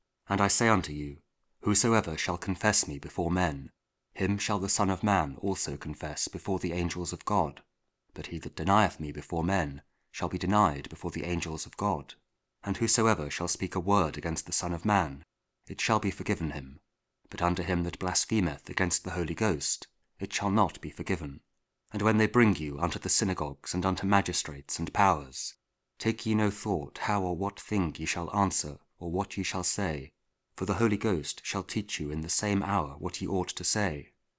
Luke 12: 8-12 – Week 28 Ordinary Time, Saturday (King James Audio Bible KJV, Spoken Word)